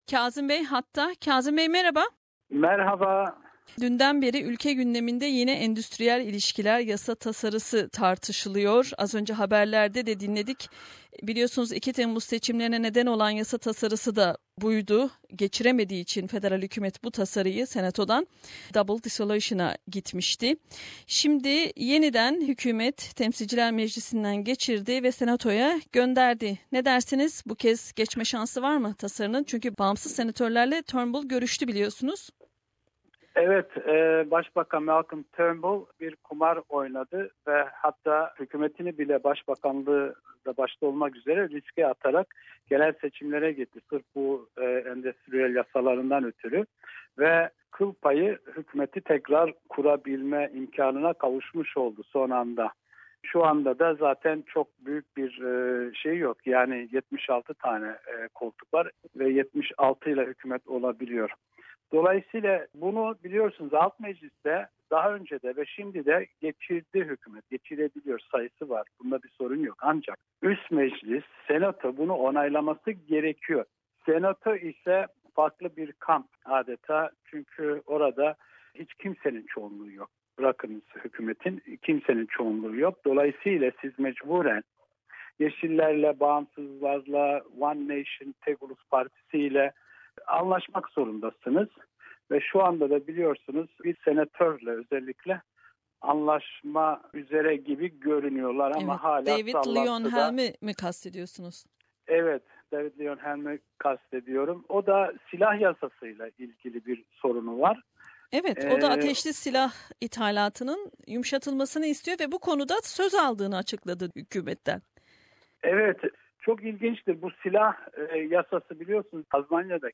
canlı yayında, Avustralya'da son günlerin önemli gündem maddelerini konuştuk.